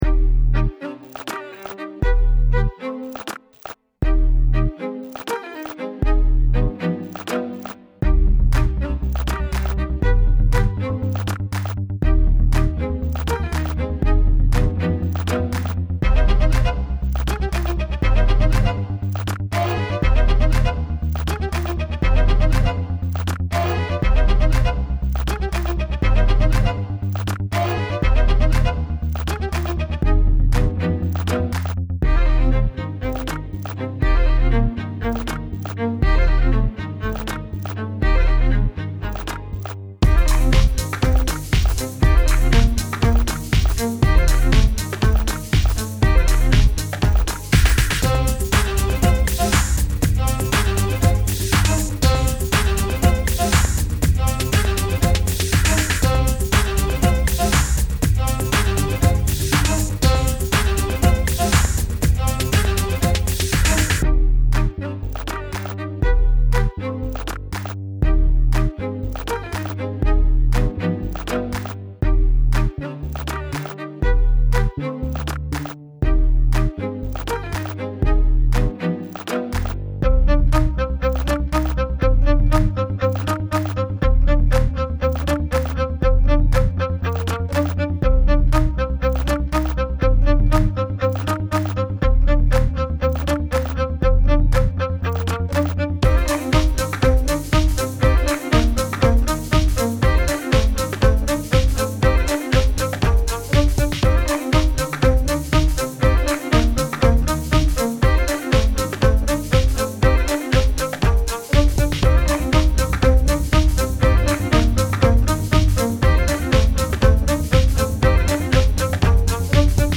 It's my second song made using logic pro and I used all pre-sampled loops (I think) I may have modified a few to work, but for the most part this was a lazy creation :P